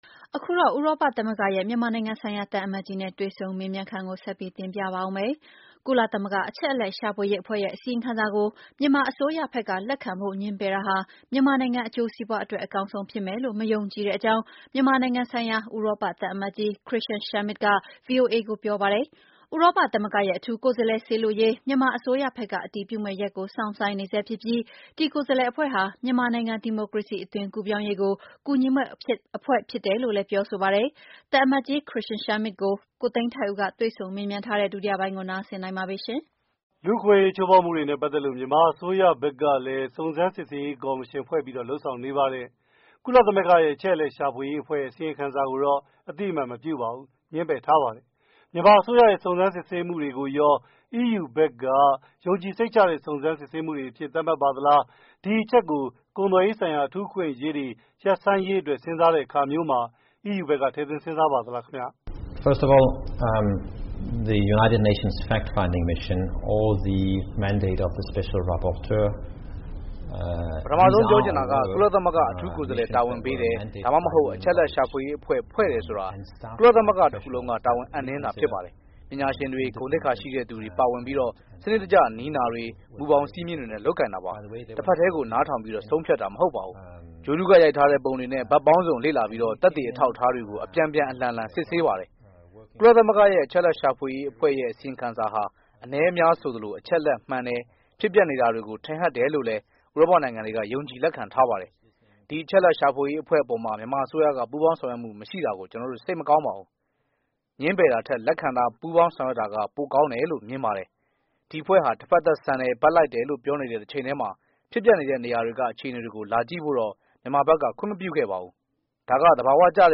EU သံအမတ်နဲ့ တွေ့ဆုံမေးမြန်းခြင်း အပိုင်း(၂)